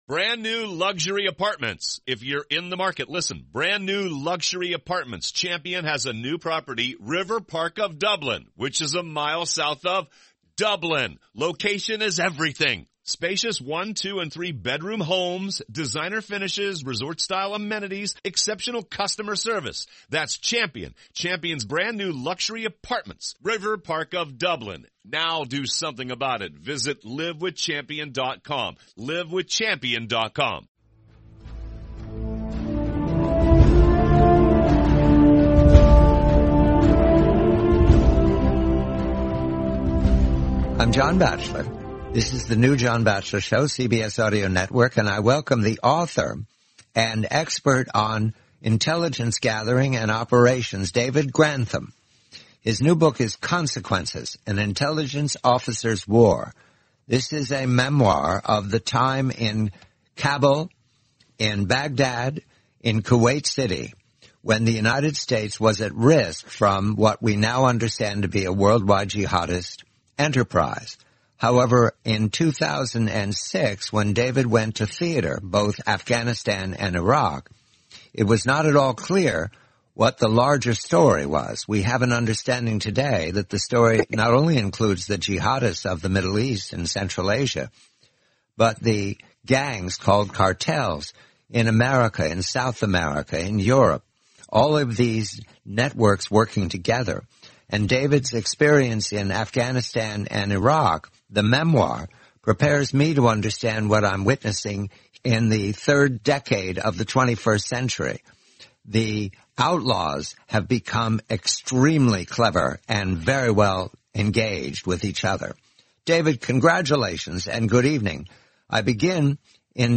The complete forty-minute interview, April 5, 2021.